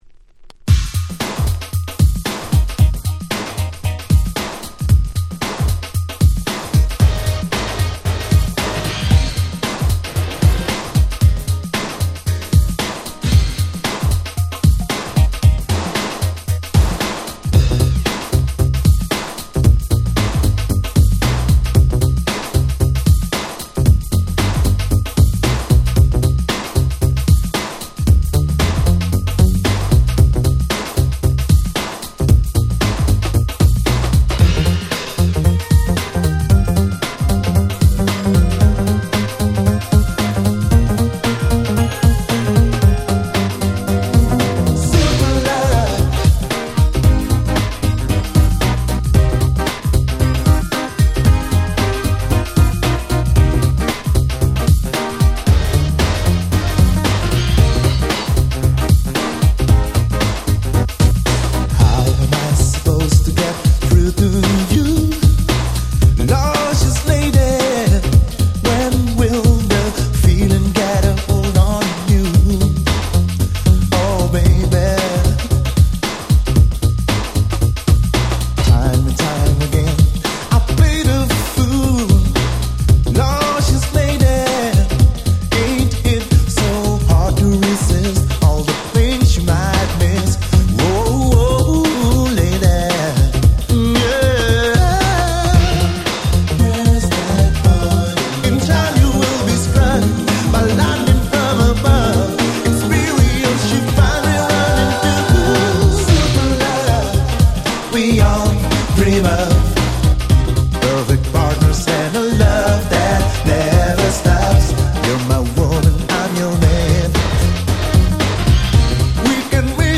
90' Nice New Jack Swing !!